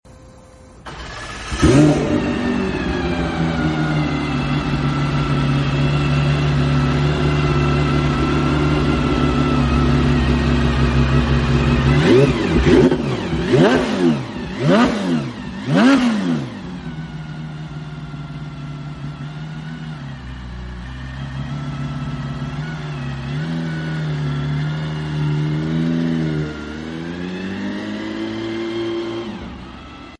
Combining forces with Williams Advanced Engineering, the centerpiece is the 4.0L 4-valve flat six engine producing approx. 500 horsepower. Limited to only 75 examples worldwide, this air-cooled, naturally aspirated 6-speed manual example is one of the all-time greats.